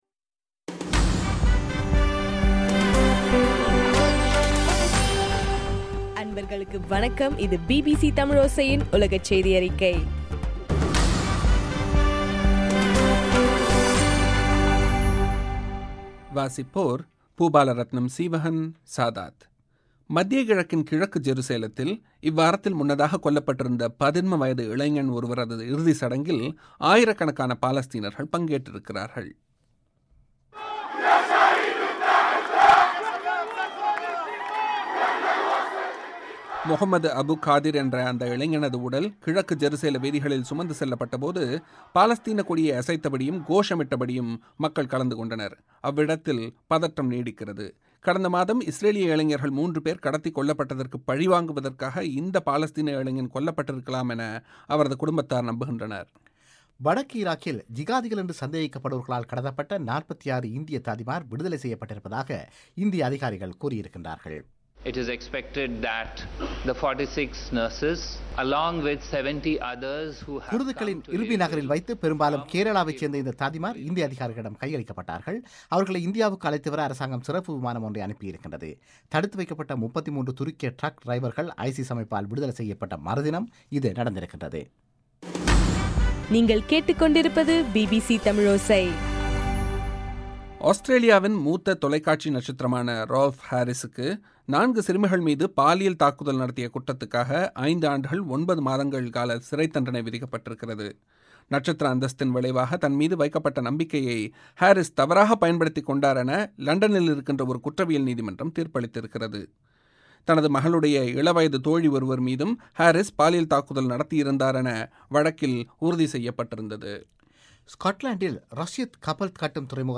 ஜூலை 4 2014 பிபிசி தமிழோசையின் உலகச் செய்திகள்